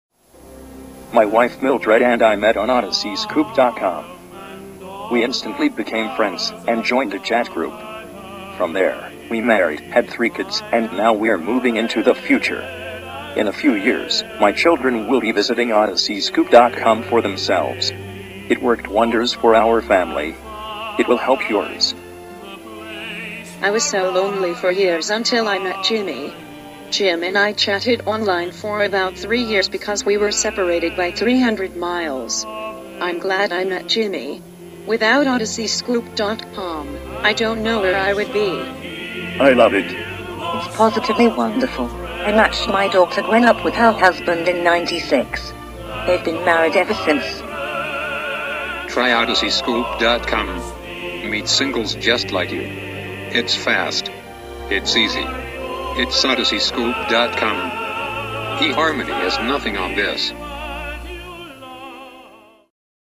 This humorous audio commercial pokes fun at online dating websites.
The voice in this audio commercial is not a real person! With advances in technology come better and better synthesized voices.